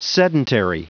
Prononciation du mot sedentary en anglais (fichier audio)
Prononciation du mot : sedentary